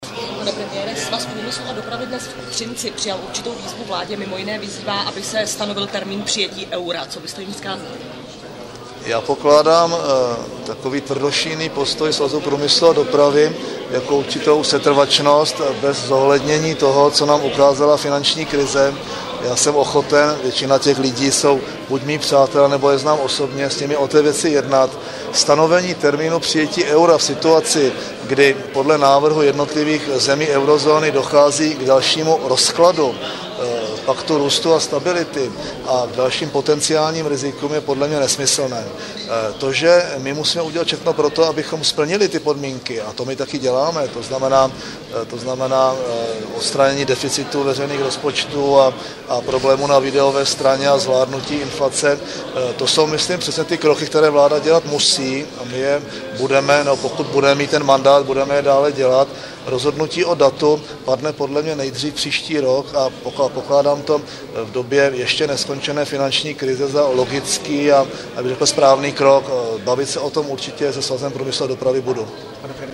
Vyplývá to ze slov předsedy vlády Mirka Topolánka na adresu Svaz průmyslu a dopravy ČR, který vyzval vládu, aby rychle a účinně reagovala na současnou finanční krizi a urychleně přijala euro.